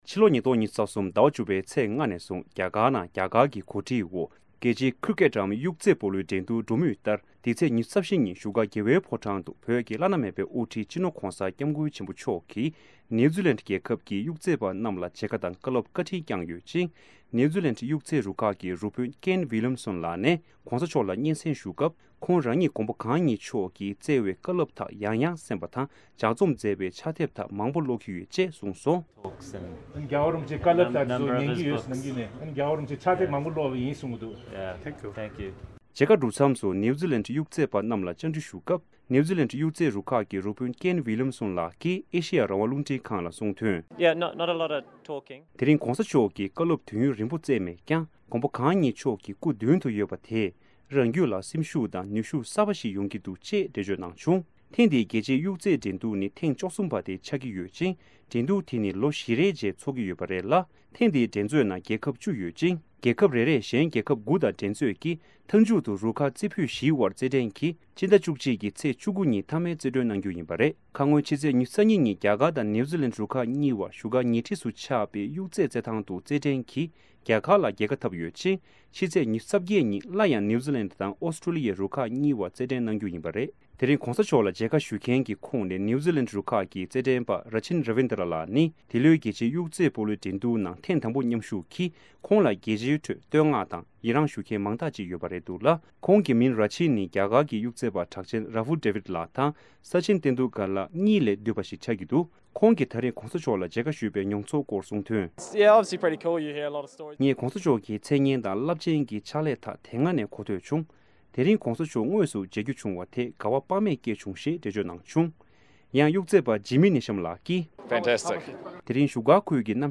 མཇལ་ཁ་གྲུབ་མཚམས་སུ་ནེའུ་ཛི་ལེན་ཌའི་དབྱུག་རྩེད་པ་རྣམས་ལ་བཅར་འདྲི་ཞུ་སྐབས།